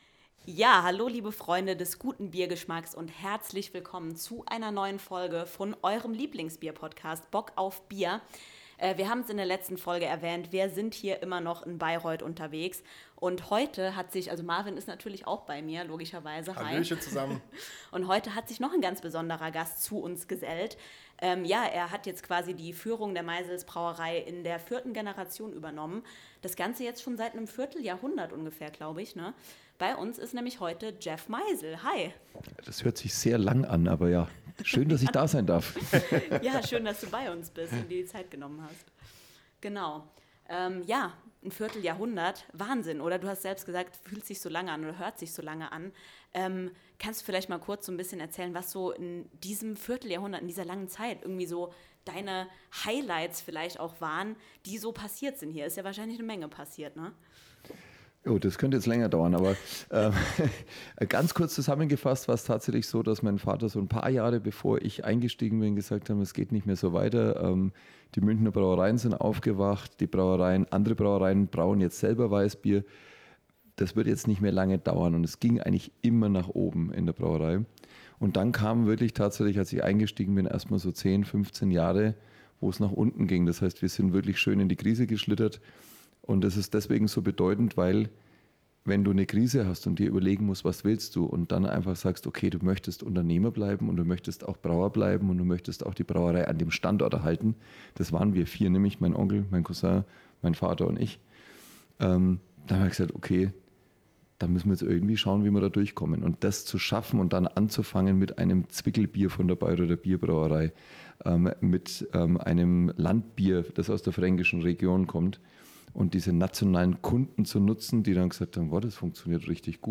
Es war ein super interessantes Gespräch für uns - aber hört doch einfach selbst!